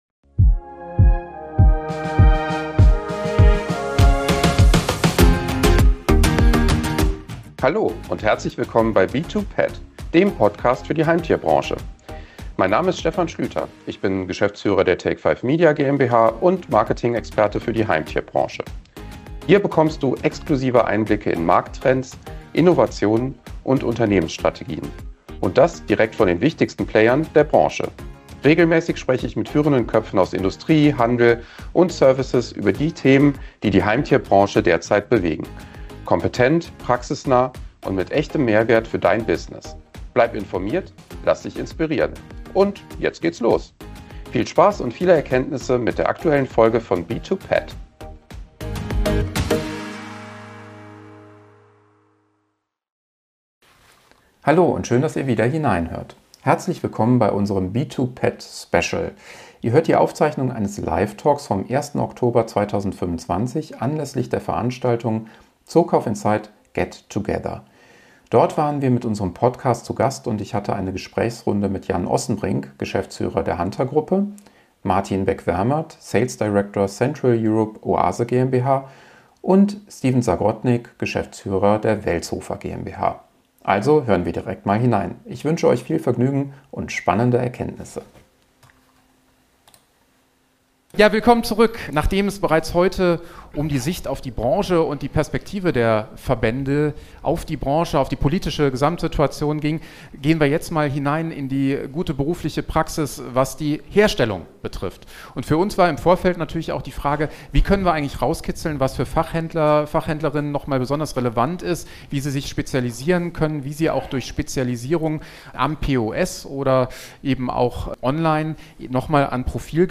Beschreibung vor 5 Monaten Am 1. Oktober 2025 zeichneten wir ein weiteres Live-Special des B2PET-Podcasts im Rahmen eines Branchenevents auf.